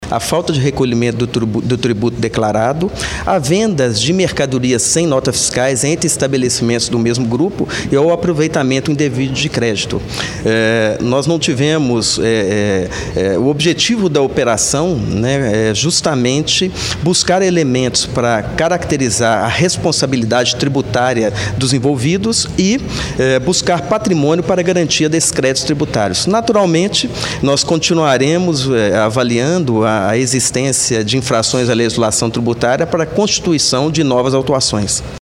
Carlos Damasceno, superintendente da Receita Estadual de Juiz de Fora, fala sobre as irregularidades.